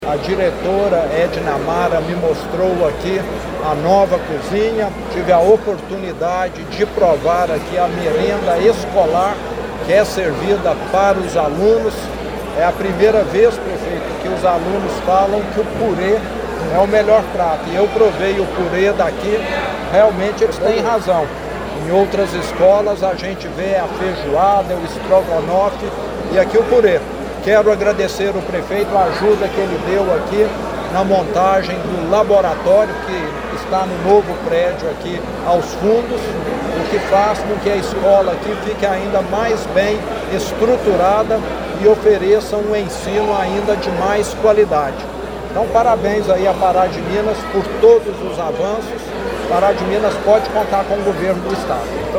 O evento ocorreu na quadra poliesportiva da Escola Estadual Nossa Senhora Auxiliadora, no bairro São Cristóvão, com a presença do governador do Estado de Minas Gerais, Romeu Zema, que ressaltou a importância de Pará de Minas para o desporto estudantil.